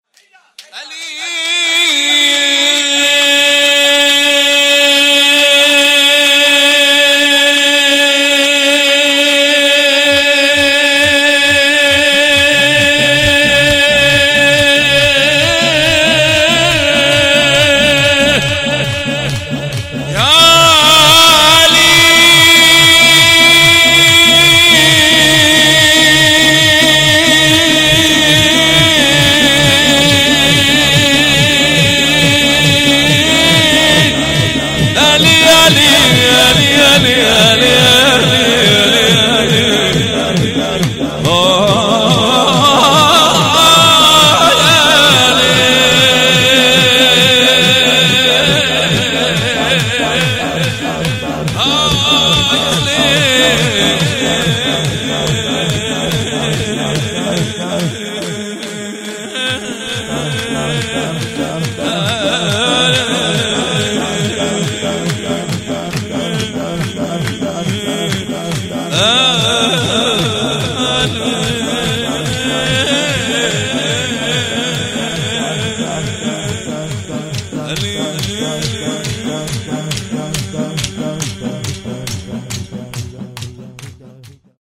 جشن مبعث